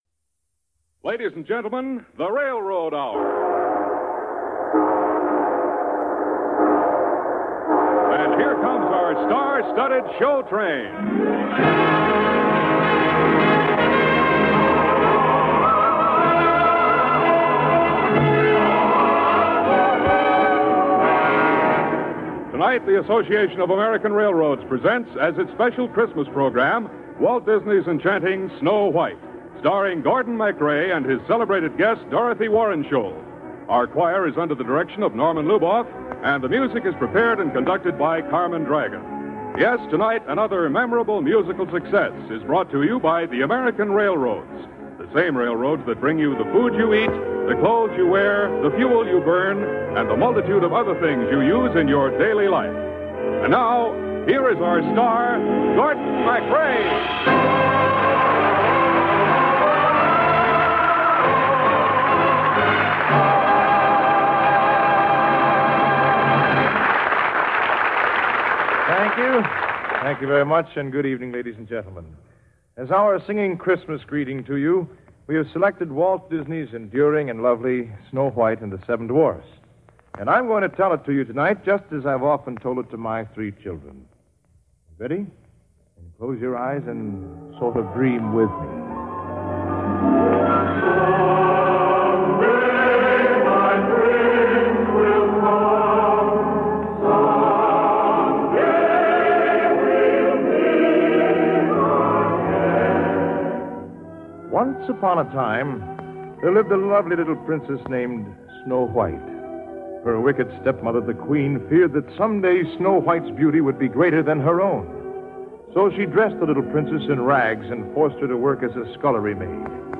The Railroad Hour was a delightful radio series that aired musical dramas and comedies from the late 1940s to the mid-1950s. Sponsored by the Association of American Railroads, the show condensed beloved musicals and operettas into shorter formats, focusing on works written before 1943.